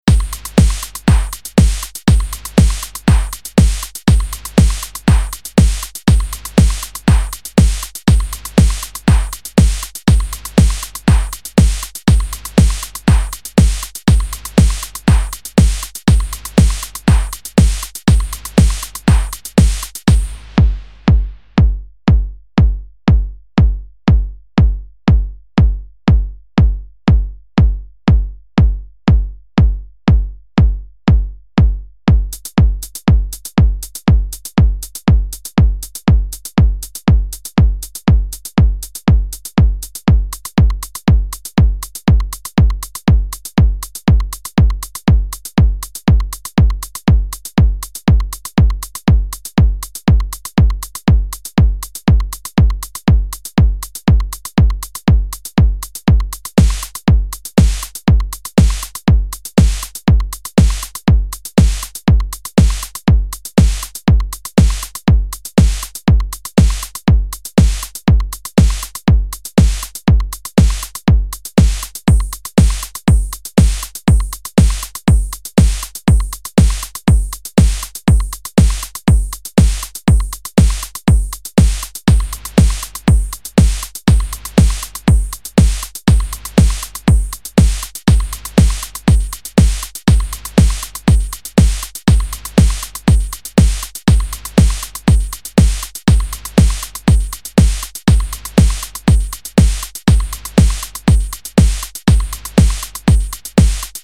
Drum & Bass
Zu Hören ist ca . 20 sec. lang ein "Quasi Reggae-Beat" im Tempo 120 Bpm. (Beat per Minute) Danach reduziert sich der Beat nur auf die Bassdrum, um das Ausschwingverhalten des Basslautsprechers neutral beurteilen zu können. Nun wird der Beat mit anderen Instrumenten angereichert, um das gesamte Soundspectrum beurteilen zu können.